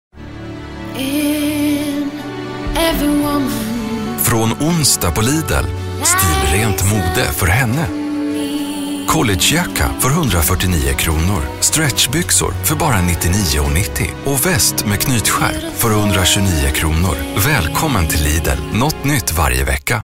Commercial 2